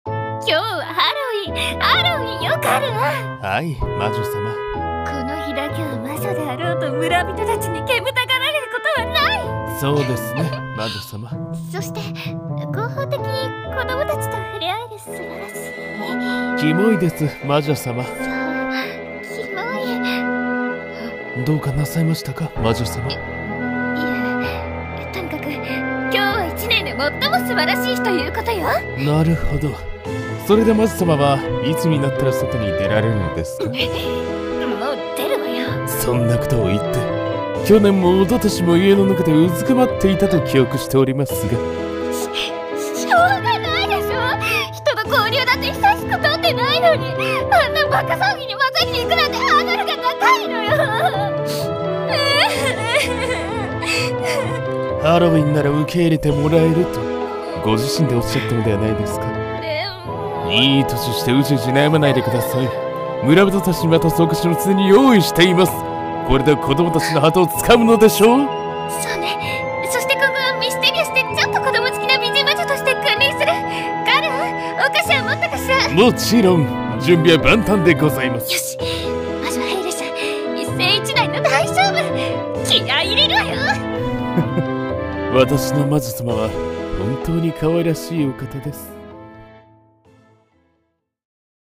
声劇台本【魔女はハロウィンで交流を図る